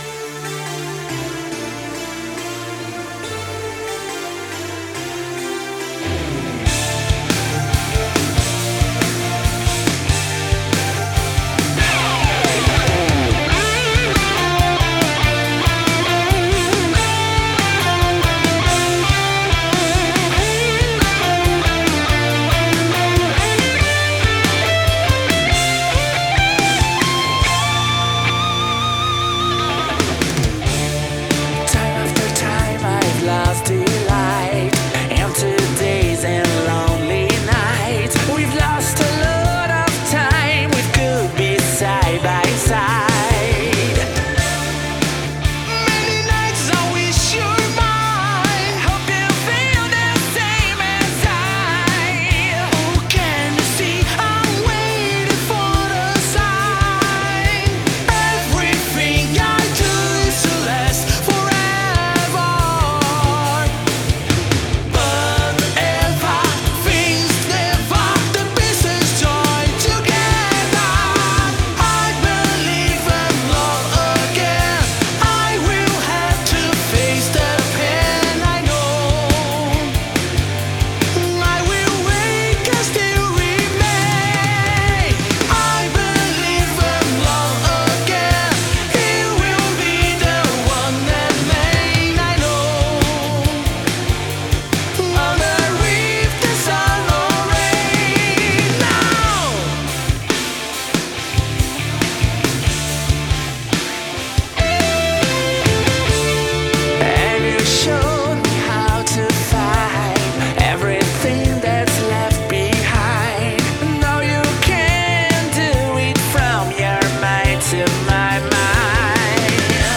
Жанр: Melodic Rock, AOR